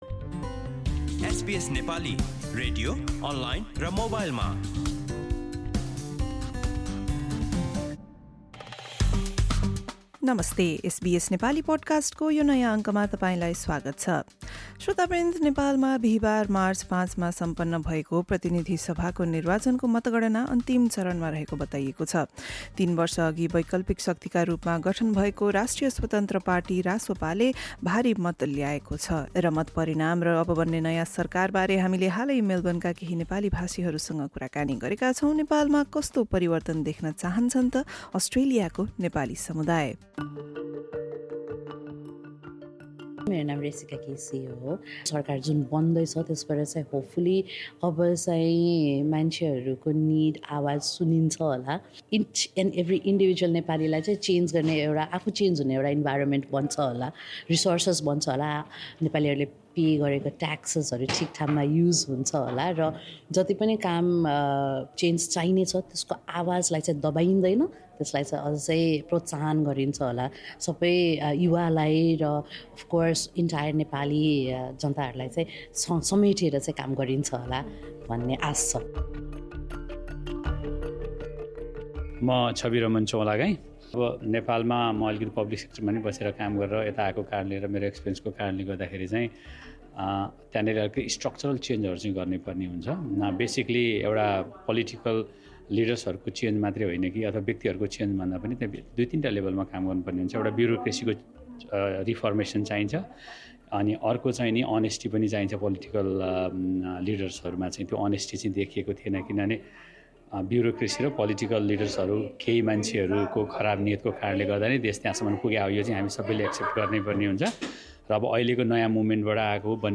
As Nepal’s election votes are being counted, the Rastriya Swatantra Party (RSP) has established itself as a major political force. Listen to our conversation with members of the Nepali community in Melbourne about their hopes and expectations from Nepal’s next government.